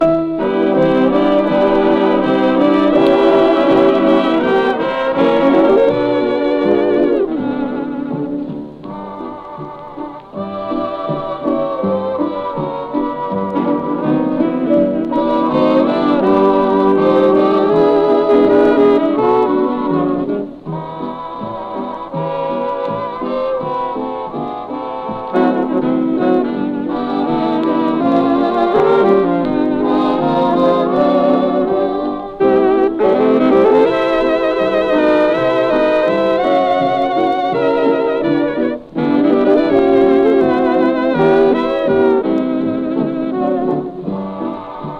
Jazz, Big Band　USA　12inchレコード　33rpm　Mono